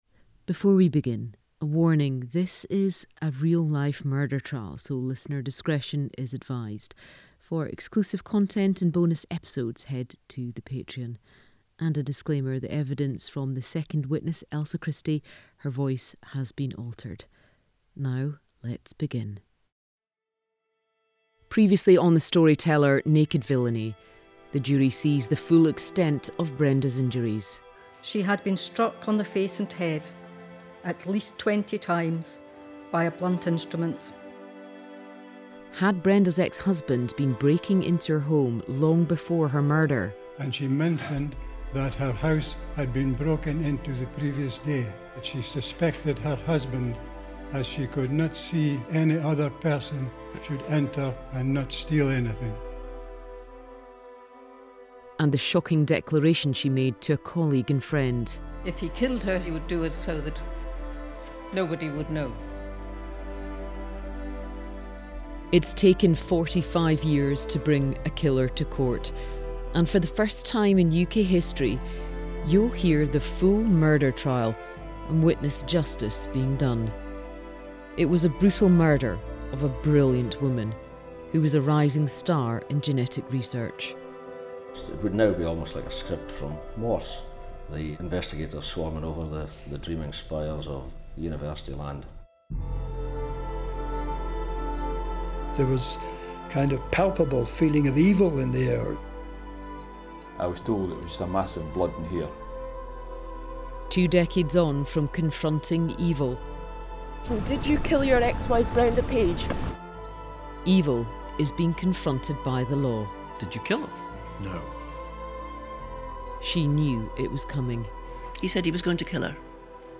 True Crime